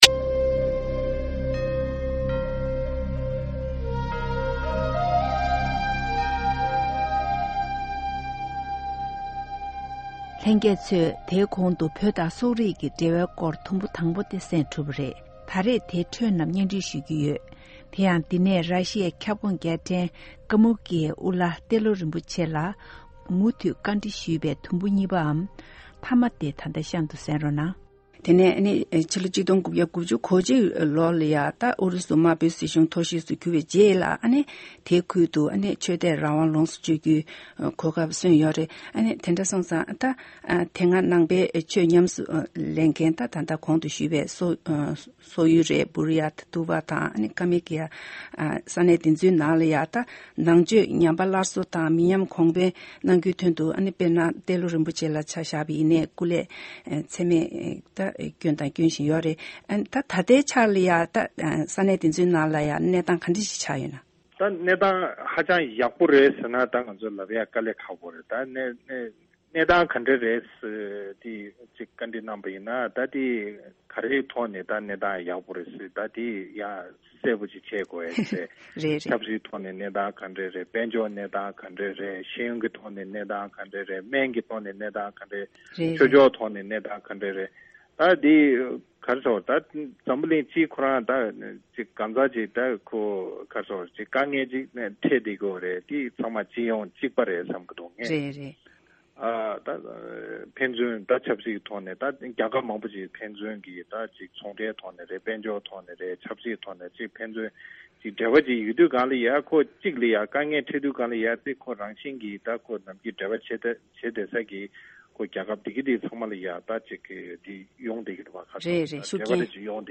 This is the second part interview with Telo Rinpoche on Tibet-Mongol relations. Rinpoche says that after the collapse of Soviet Union in 1992, he joined the Dalai Lama on his first visit to Kalmykia, a region once rich in Buddhist heritage destroyed in the 1930s by the communist. Under the Dalai Lama's guidance, Rinpoche took the responsibility of leading a Buddhist revival within the approximately 160,000 Kalmyks who live in Kalmykia.